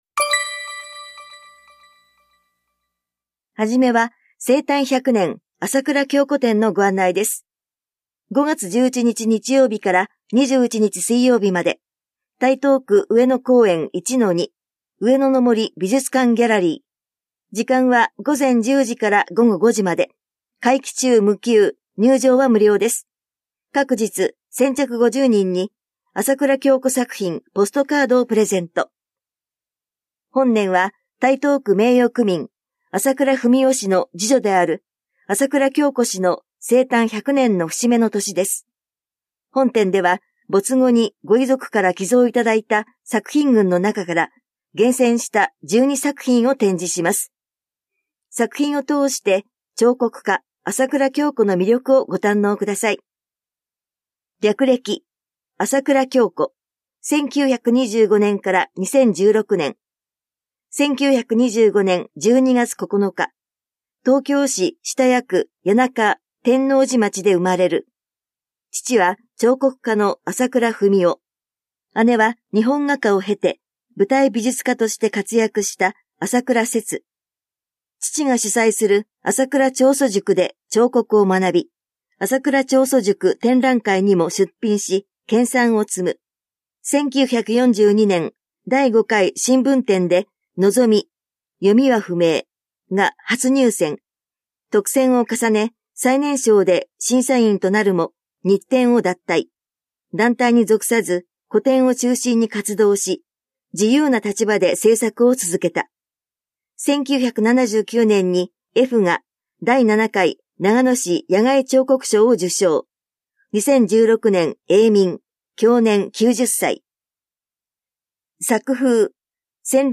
広報「たいとう」令和7年4月20日号の音声読み上げデータです。